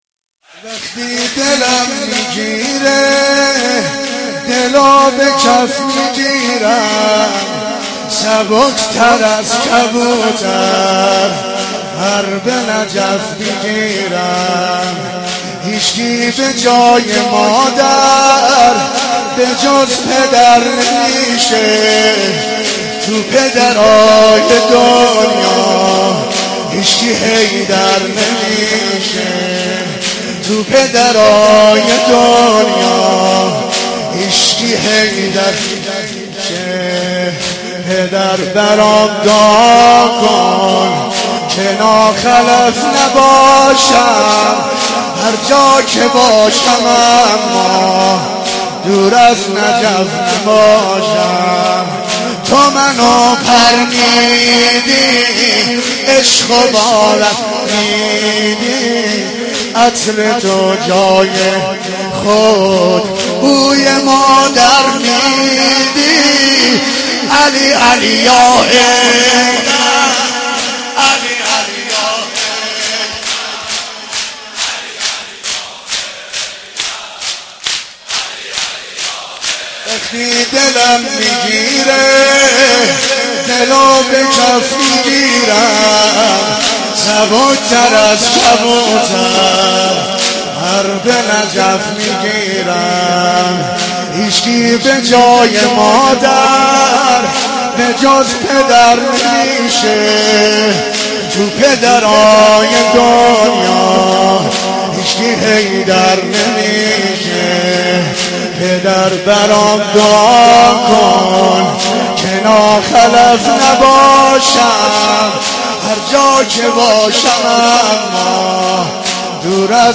رمضان 89 - سینه زنی 1
رمضان 89 - سینه زنی 1 خطیب: حاج عبدالرضا هلالی مدت زمان: 00:03:43